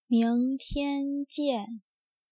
Míngtiān jiàn
ミン ティェン ジィェン